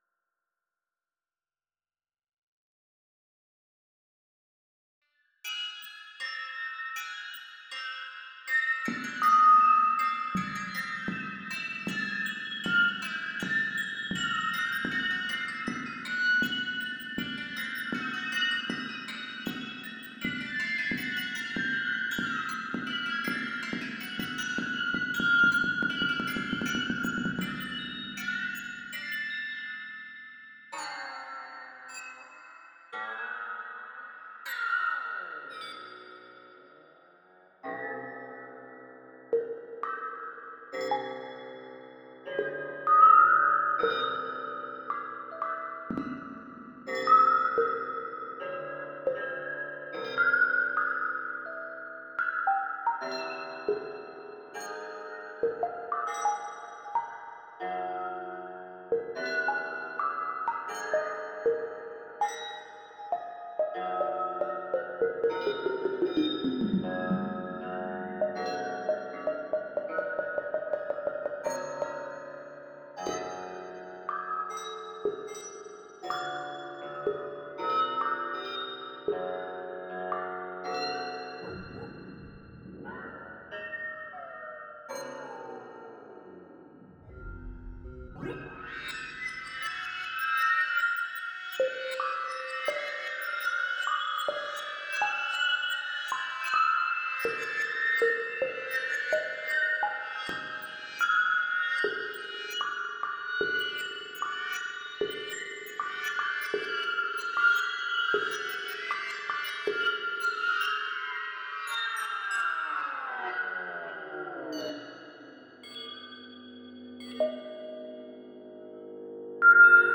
• Utilizar solo las notas DO-RE-MI-FA#-SOL#-LA# (escala hexatónica)
• Componer 8 compases de 4/4 en clave de Sol
Con el primer material obtenido he compuesto una variación de la pieza utilizando sonidos electrónicos: